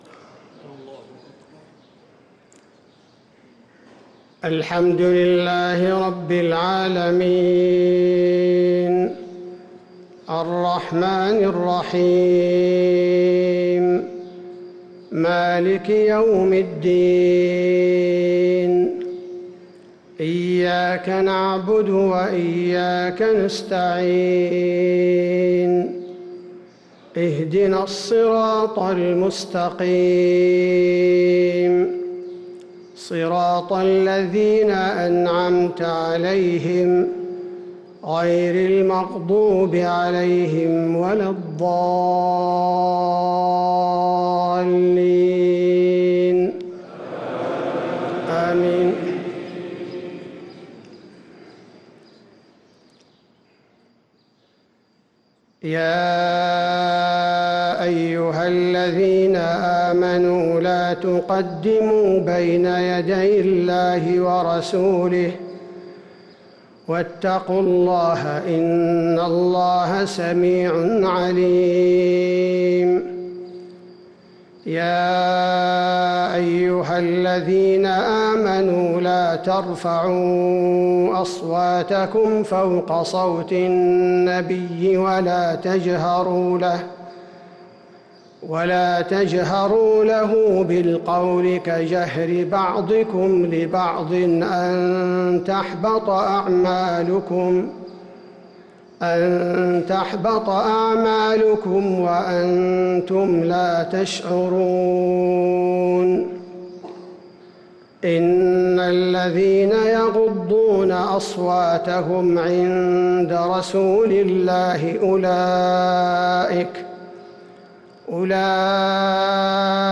صلاة الفجر للقارئ عبدالباري الثبيتي 21 رمضان 1444 هـ
تِلَاوَات الْحَرَمَيْن .